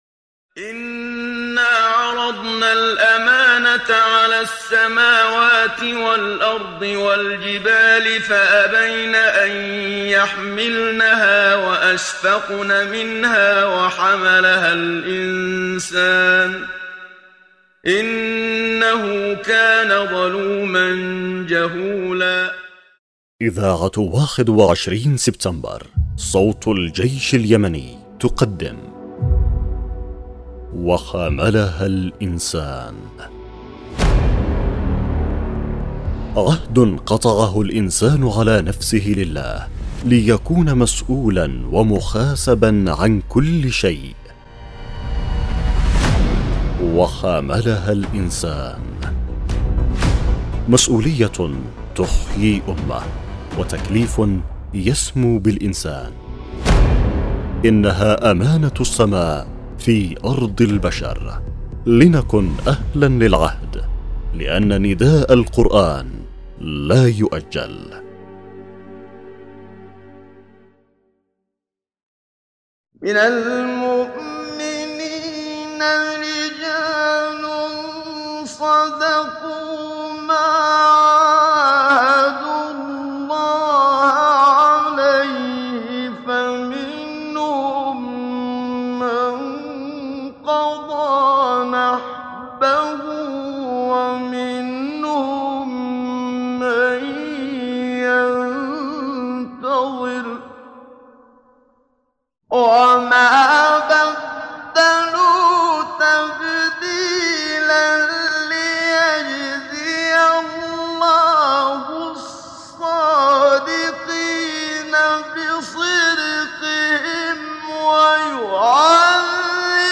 برنامج إذاعي يعمل على مناقشة قضايا المسؤولية تجاه كل شيء المسؤولية تجاه الاسرة والأولاد وتجاه الدين والإسلام وتجاه المقدسات وتجاه الشهداء وكل قضية الانسان مسؤول عليها امام الله مع شرح ونقاش عن كل الجوانب التي تشملها تلك المسؤولية